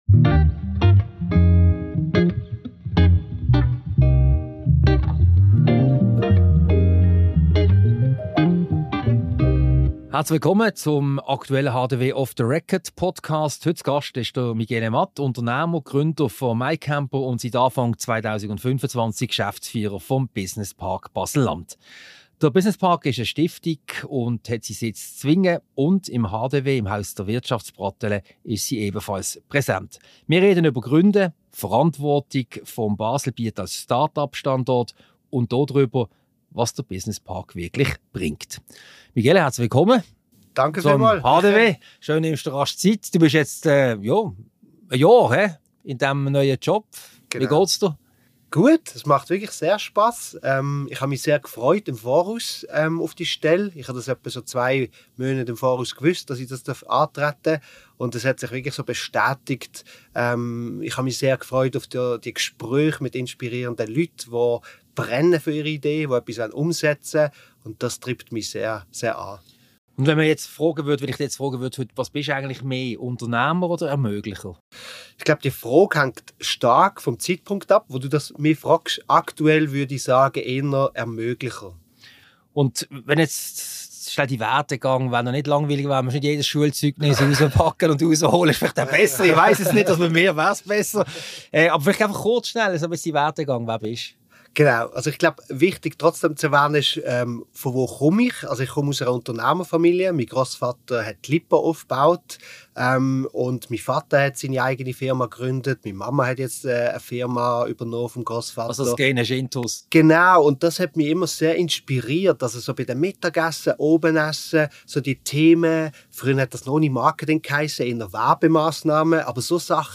Ein Gespräch darüber, was es bedeutet, ein Unternehmen zu gründen, über Start-ups im Baselbiet und natürlich über den Business Park Baselland.